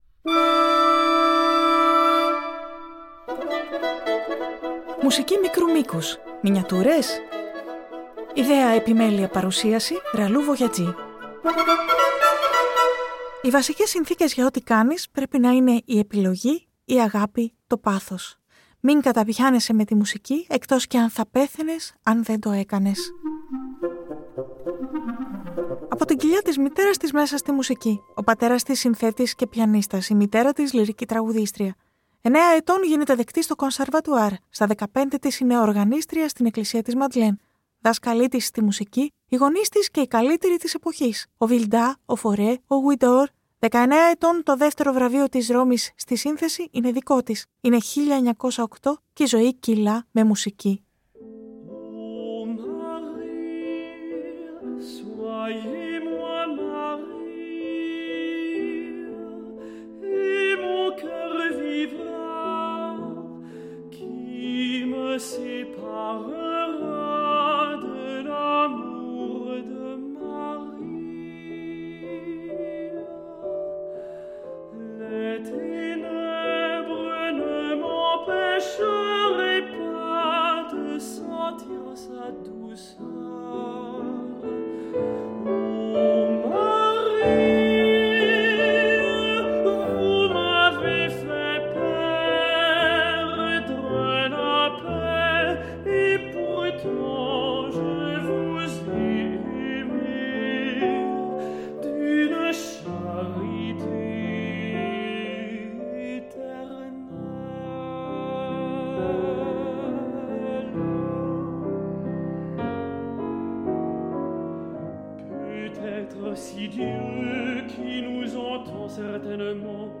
* όλες οι φράσεις που ακούγονται στην αρχή κάθε μέρους της εκπομπής είναι της Nadia Boulanger** τα κείμενα που ακούγονται σε πρώτο πρόσωπο είναι λόγια των μαθητών της